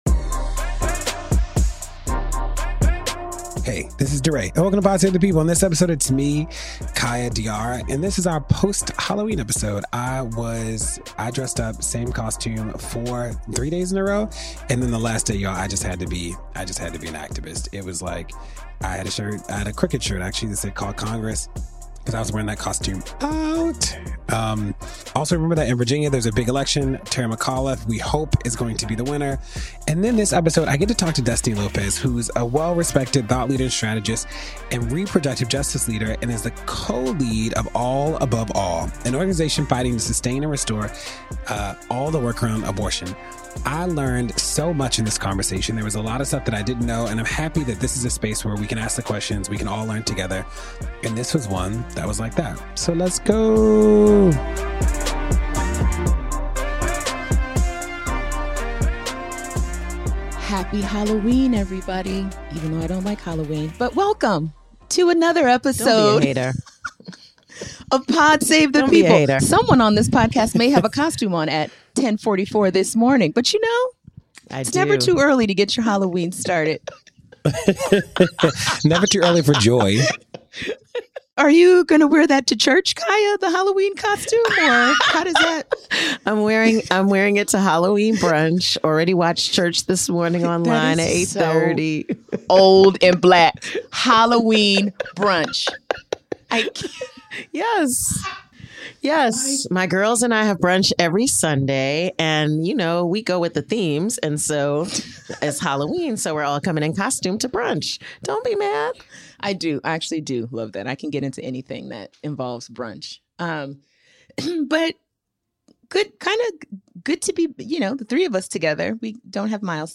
DeRay interviews